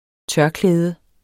Udtale [ ˈtɶɐ̯- ]